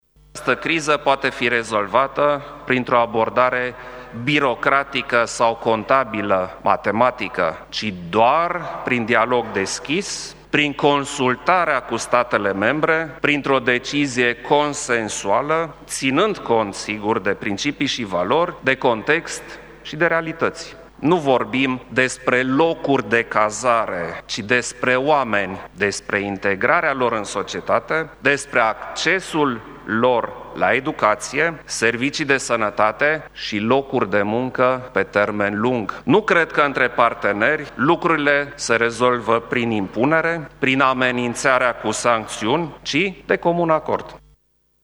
Într-un discurs susţinut cu puţin timp în urmă, în faţa plenului reunit al celor două Camere ale Parlamentului, preşedintele Klaus Iohannis a declarat că statele membre trebuie să-şi stabilească singure numărul de persoane pe care-l pot primi: